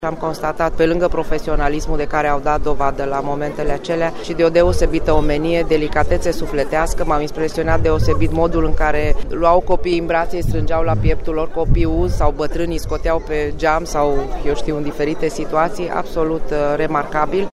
Cu acest prilej, a fost organizată o ceremonie militar religioasă astăzi aşa cum se întâmplă în fiecare an, de 13 septembrie.
Printre cei care au luat cuvântul a fost şi prefectul Florenţa Albu. Aceasta s-a arătat impresionată de devotamentul pompierilor din zilele în care judeţul era acoperit de ape:
Florenta-si-pompierii.mp3